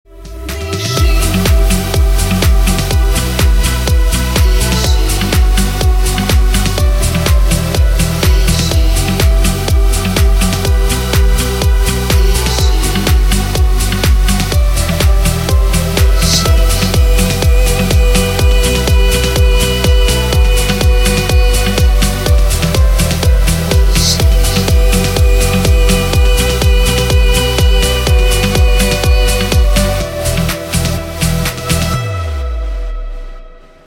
Клубные Рингтоны
Поп Рингтоны